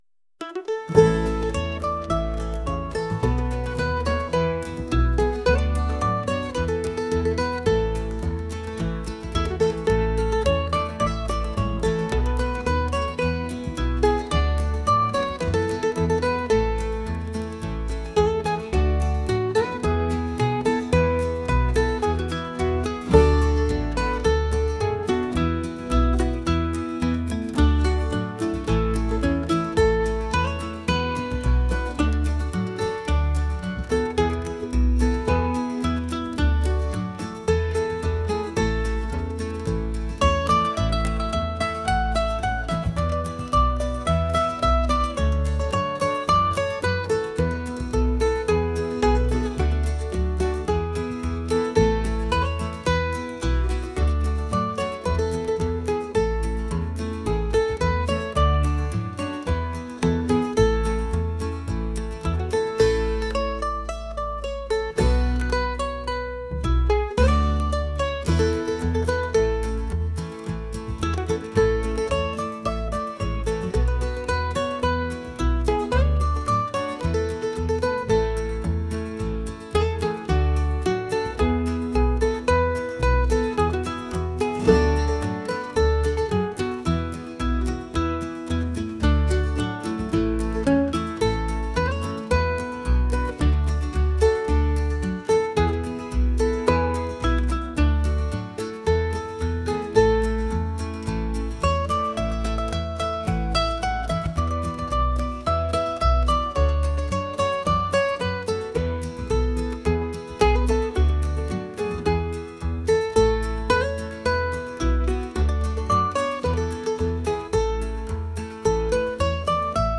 traditional | lively | folk